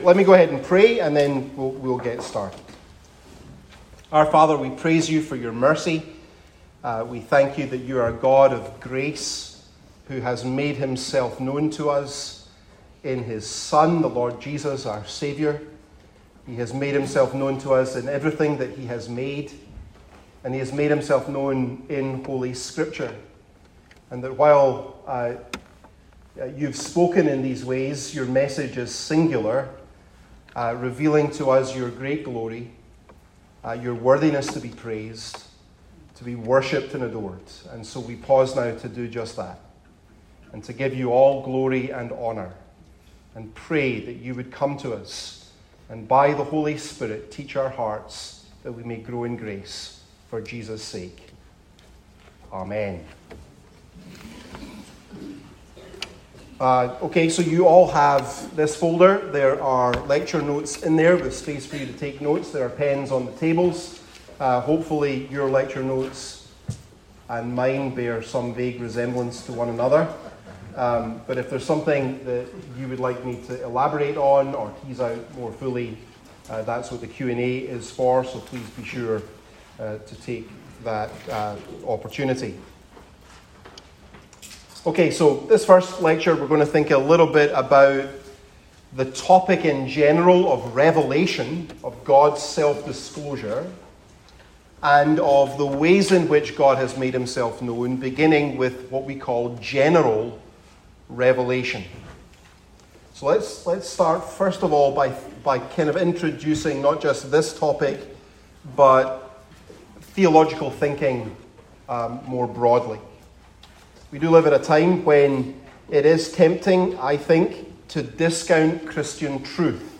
The-Doctrine-of-Scripture-Lecture-1-General-Revelation.mp3